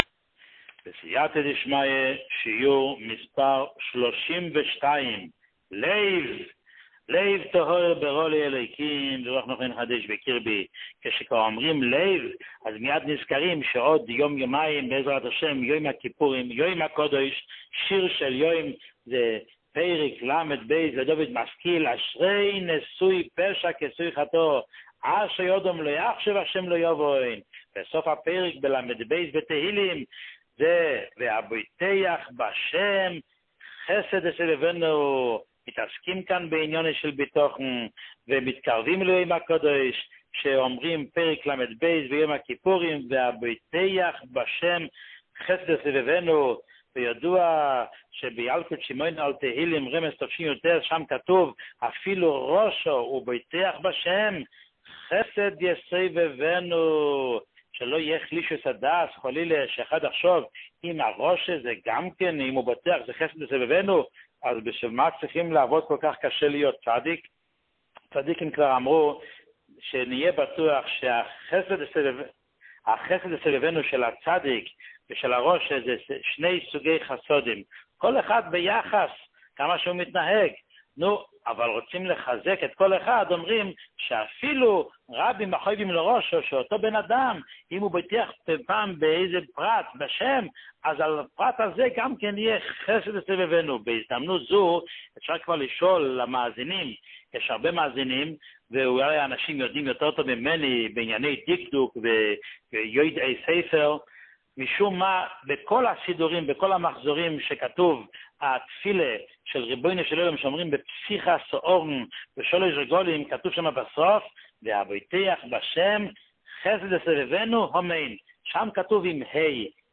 שיעור 32